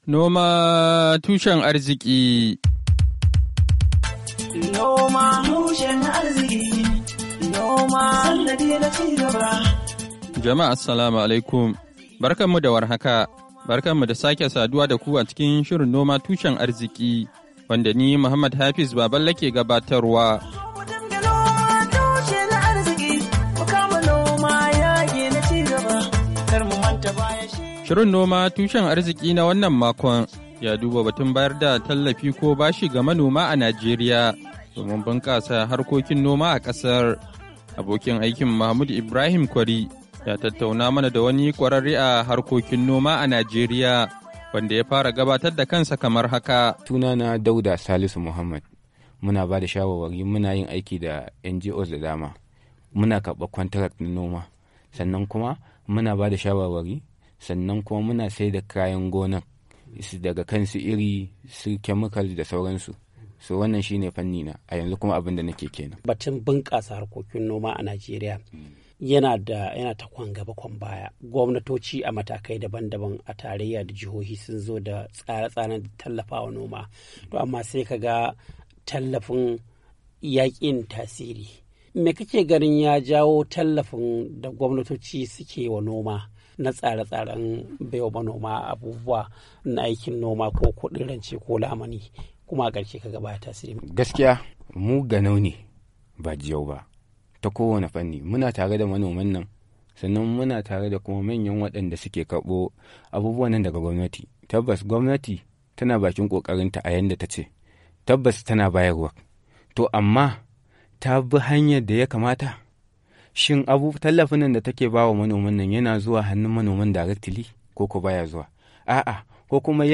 NOMA TUSHEN ARZIKI: Hira Da Kwararre Kan Harkokin Noma A Kan Batun Bunkasa Noma A Najeriya.mp3